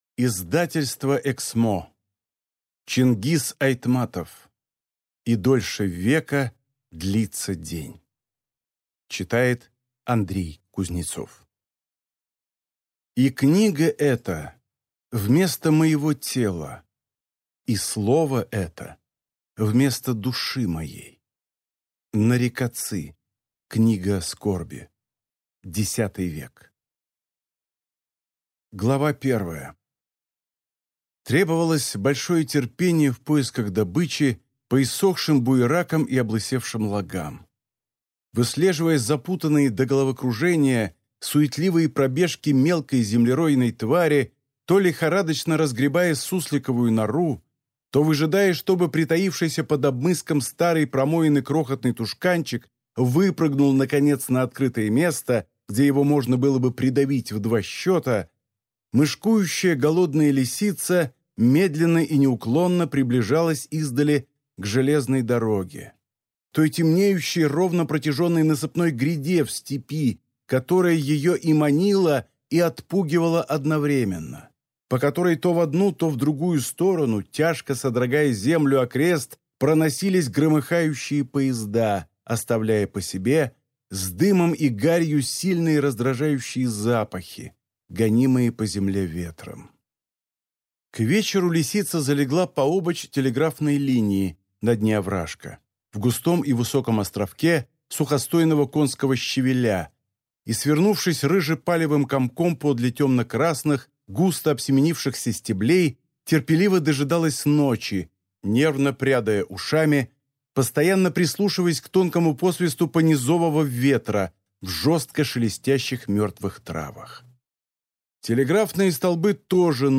Аудиокнига И дольше века длится день…